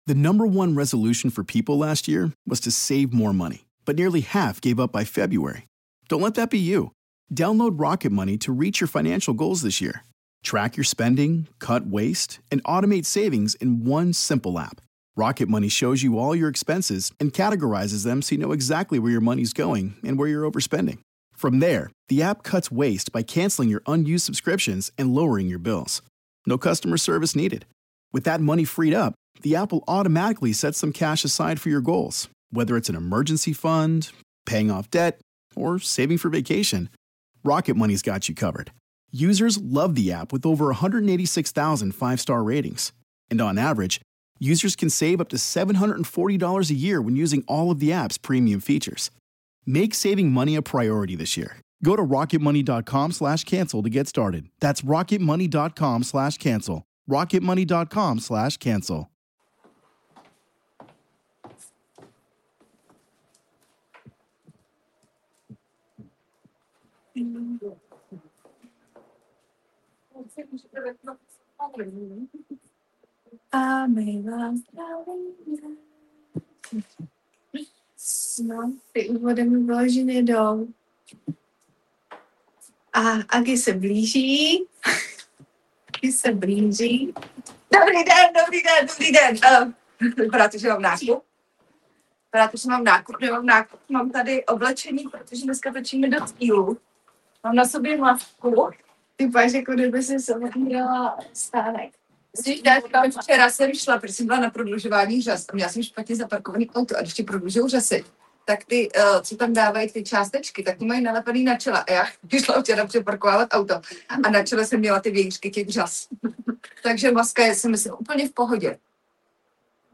LIVE in STUDIO
Záznam živáku ze šatny našeho studia, kde se připravujeme na natáčení.